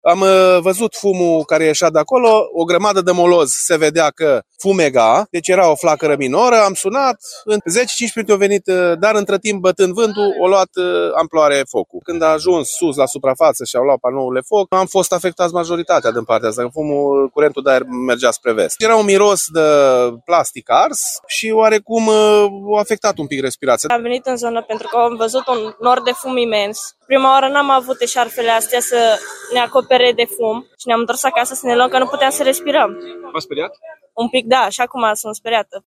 Martorii spun că incendiul a izbucnit de la niște deșeuri din apropiere, iar apoi s-a extins în curtea și interiorul complexului comercial, pe sute de metri pătrați.
VOX-incendiu-ok.mp3